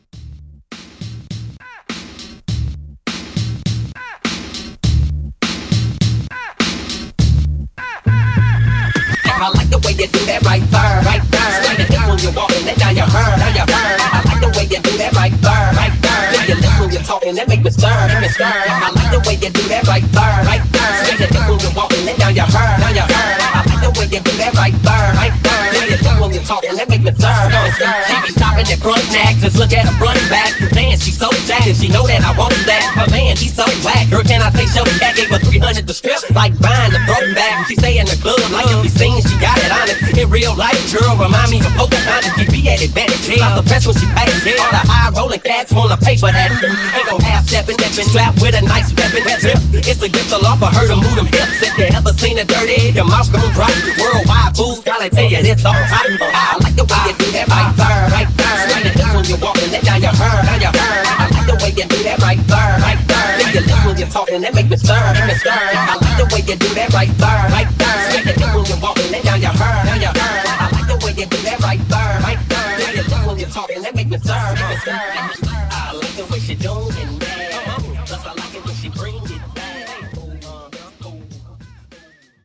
Genre: BOOTLEG
Clean BPM: 126 Time